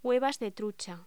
Locución: Huevas de trucha
voz